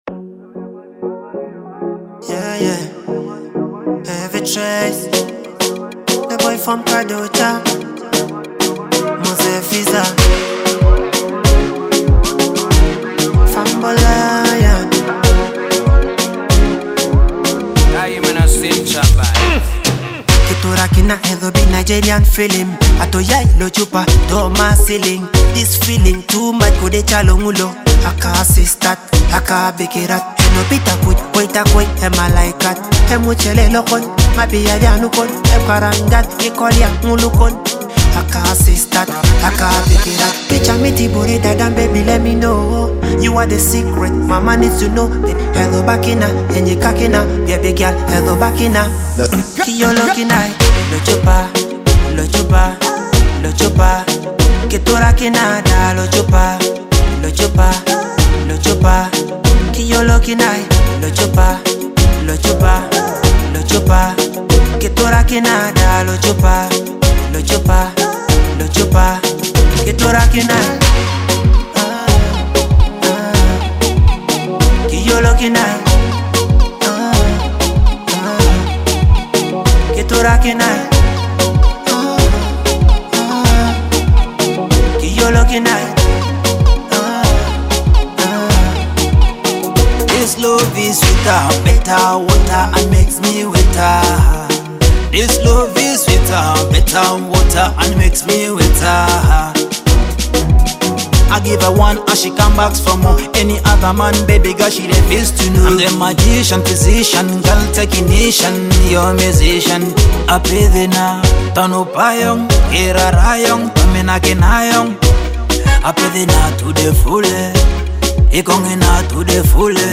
Teso music
a captivating blend of rhythmic beats and powerful vocals.